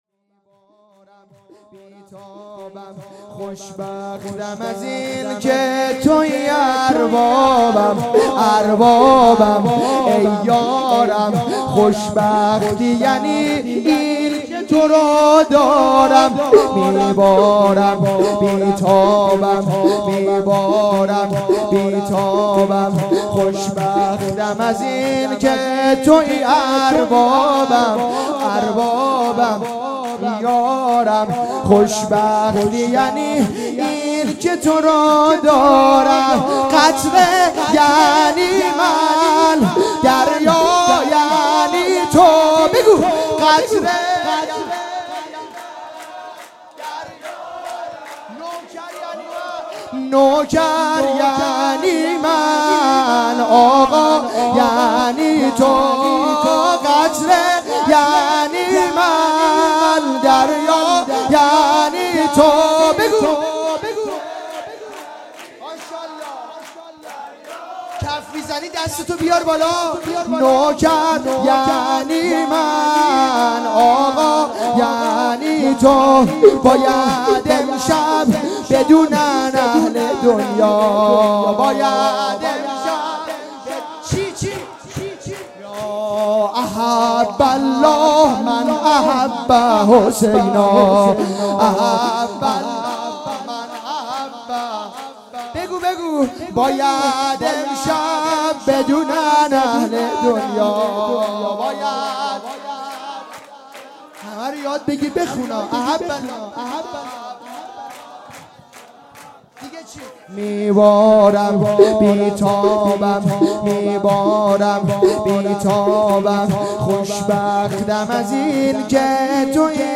جشن ولادت امام حسین علیه السلام